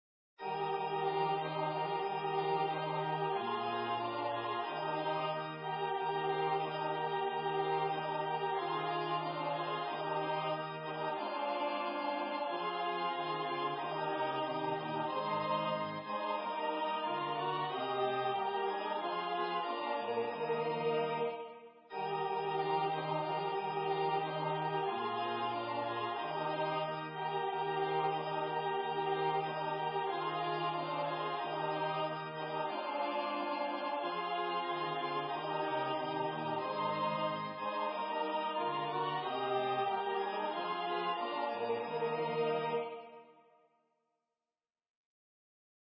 Title: O Dear Little Children Composer: Anonymous Lyricist: Translated by Sister Jeanne Marie S.N.D.create page Number of voices: 2vv Voicing: SA Genre: Sacred, Unknown
Language: English Instruments: Organ